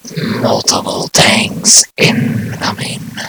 mvm_tank_alerts11.mp3